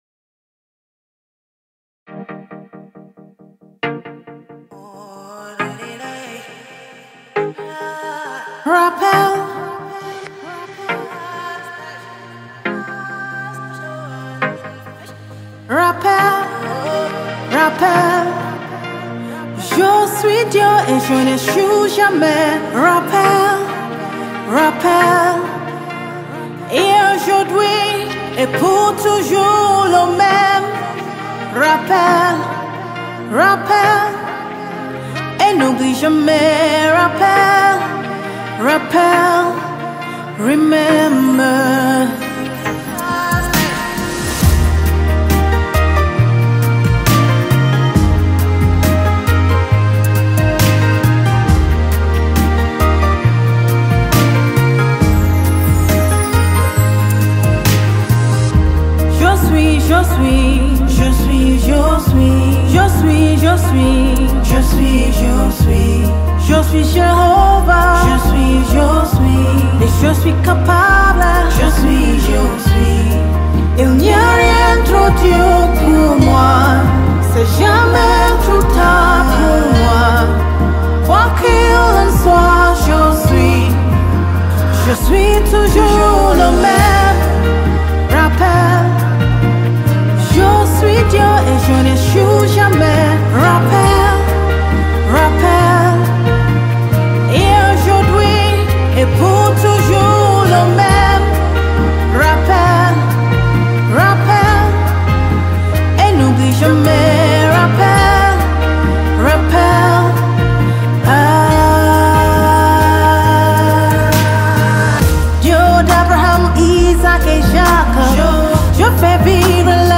Genre:Gospel